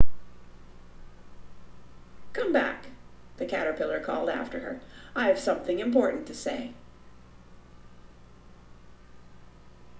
在以下所有录音中、我将麦克风放置在距离显示器约6英寸的位置。
我不再听到"数字"的声音。
仍然存在噪声。 但要 低得多。